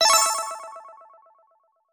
GMTK_SFX_LVL-START_MASTER.mp3